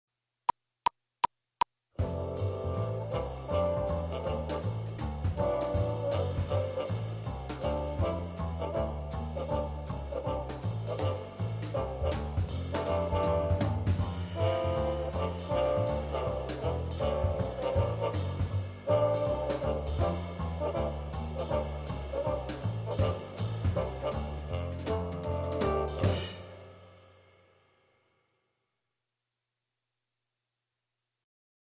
반주